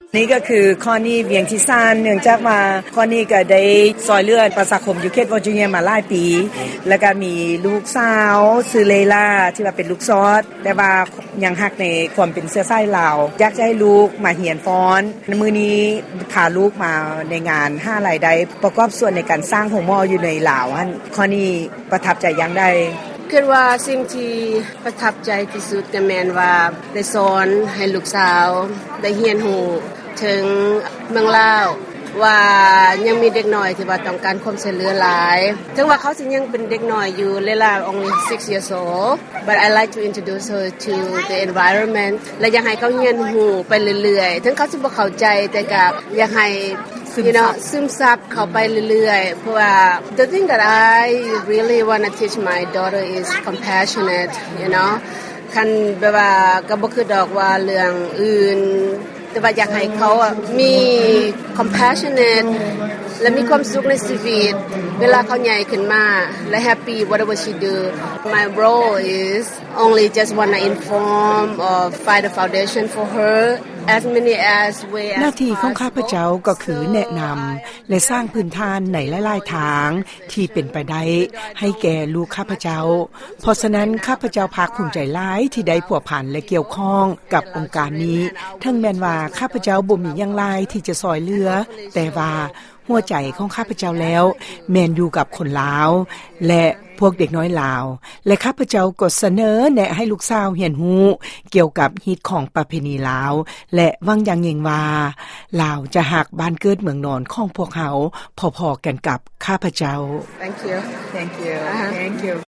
ໃນຄັ້ງນີ້ ດັ່ງບົດສຳພາດຕໍ່ໄປນີ້: